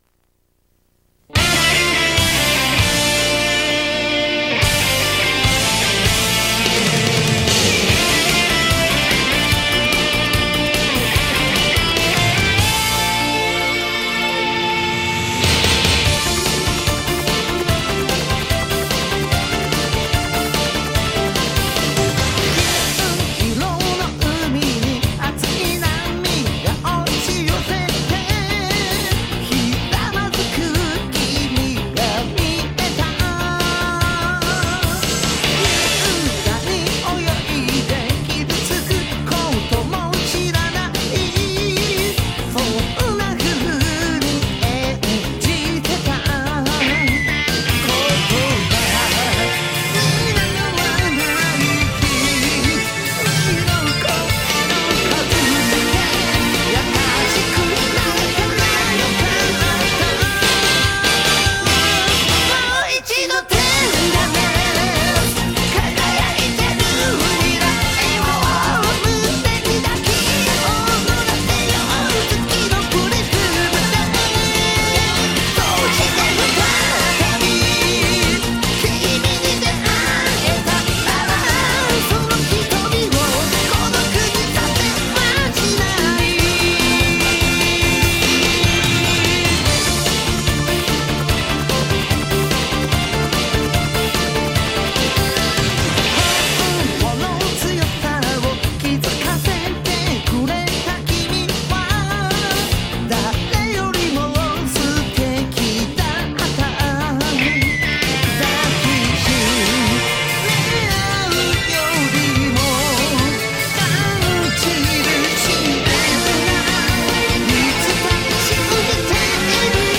風邪で気管支やられていたタイミングで歌いだしたので、ガラガラになり時間がかかった。